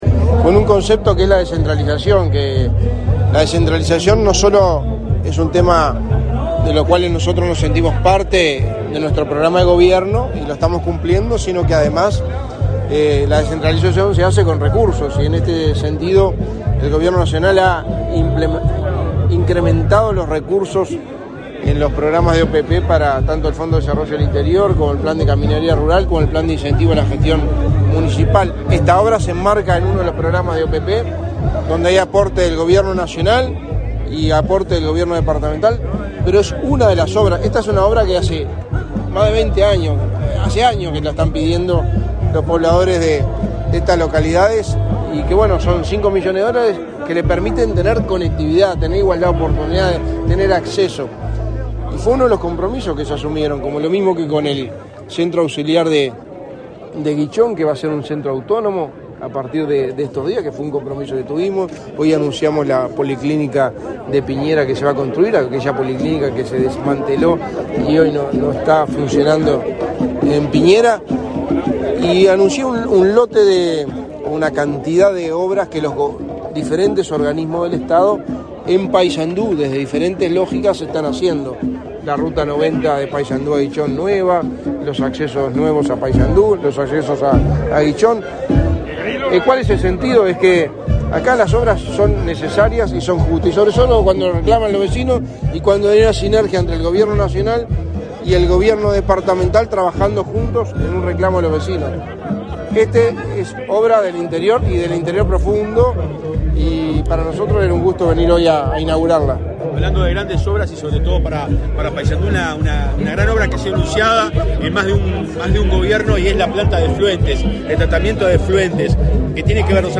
Declaraciones a la prensa del secretario de la Presidencia, Álvaro Delgado
Tras participar en la inauguración obras de pavimentación en camino Piñera-Merino- Morató, en el departamento de Paysandú, este 26 de agosto, el
Delgado prensa.mp3